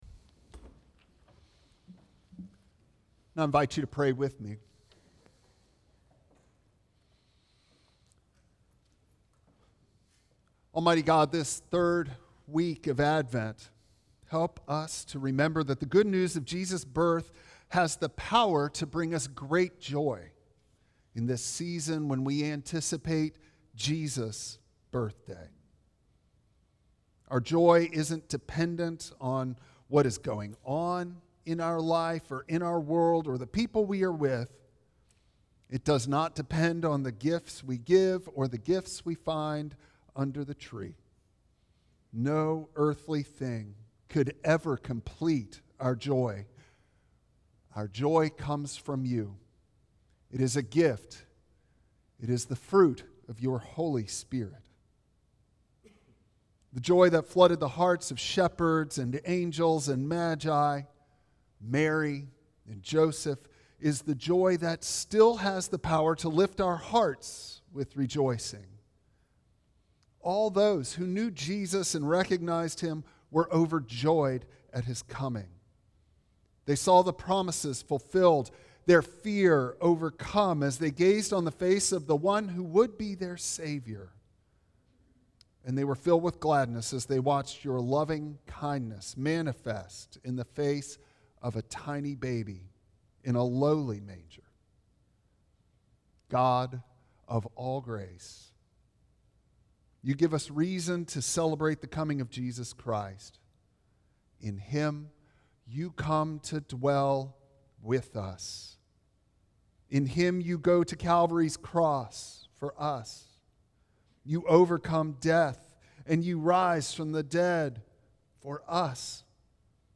Passage: Matthew 1:22-25 Service Type: Traditional Service